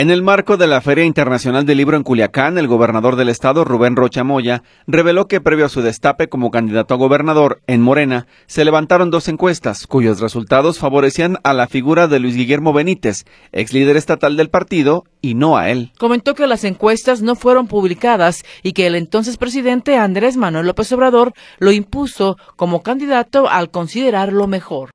En el marco de la Feria Internacional del Libro en Culiacán, el gobernador del estado, Rubén Rocha Moya reveló que previo a su destape como candidato a gobernador, en Morena se levantaron dos encuestas, cuyos resultados favorecían a la figura, de Luis Guillermo Benítez, exlíder estatal del partido y no a él. Comentó que las encuestas no fueron publicadas y que el entonces presidente, Andrés Manuel López Obrador lo impuso como candidato al considerarlo mejor.